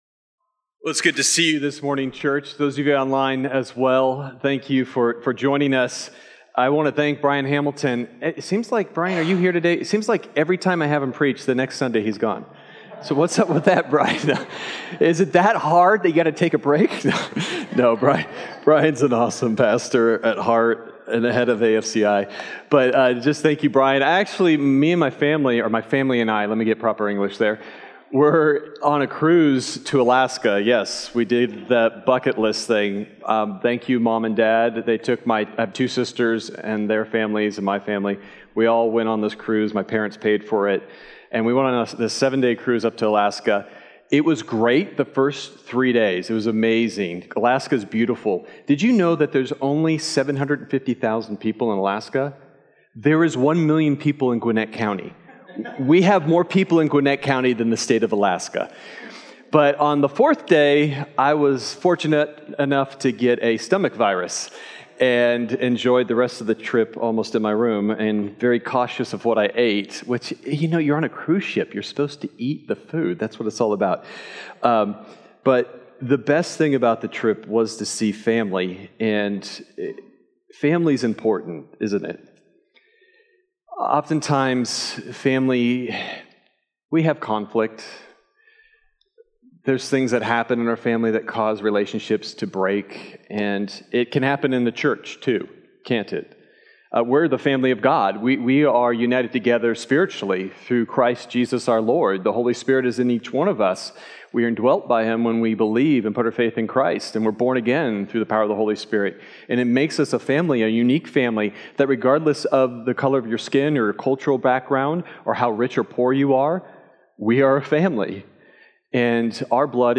Sermon Detail
August_11th_Sermon_Audio.mp3